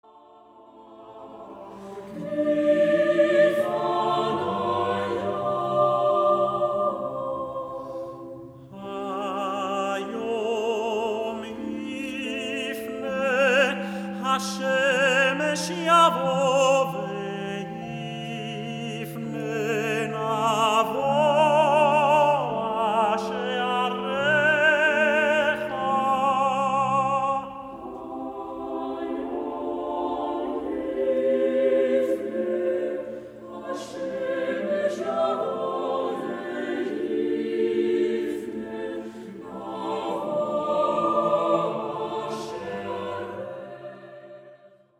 Cantorial and Choral masterpieces
recorded in Berlin